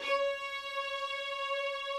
strings_061.wav